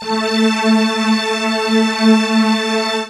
strTTE65012string-A.wav